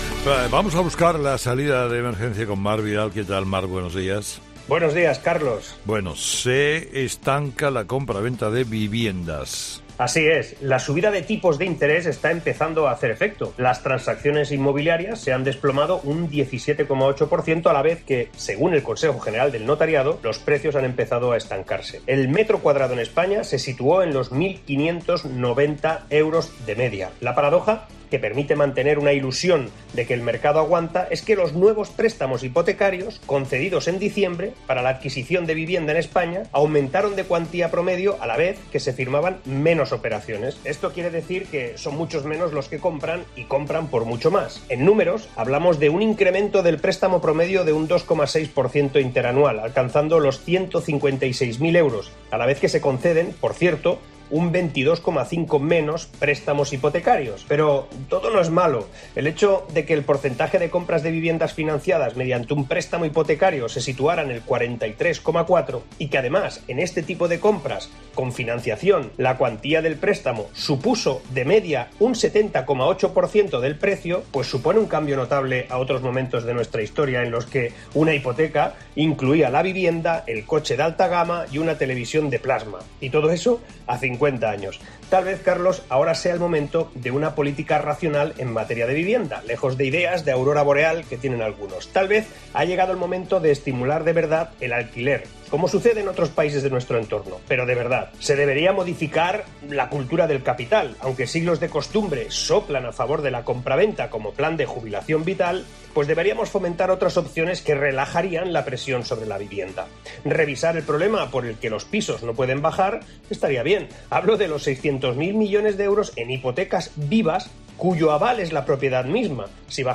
El analista económico da una de las soluciones para reducir el precio de la vivienda, en 'Herrera en COPE'